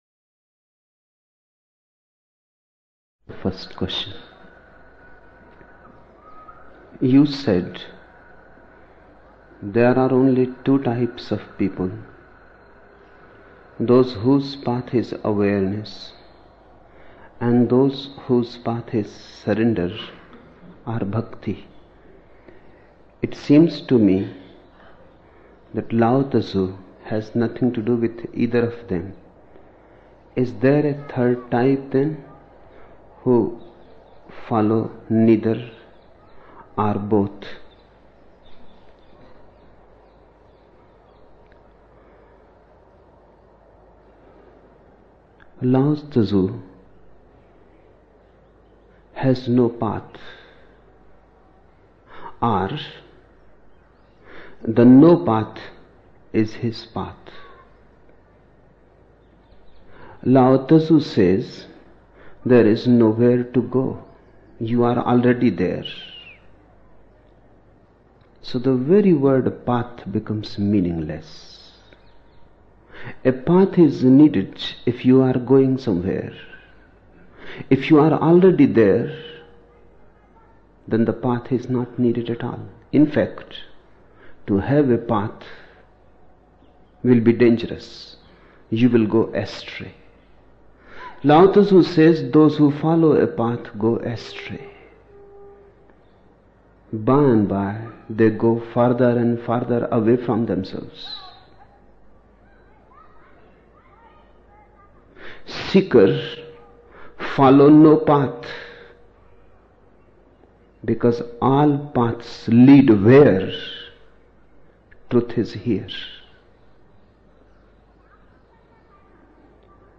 24 October 1975 morning in Buddha Hall, Poona, India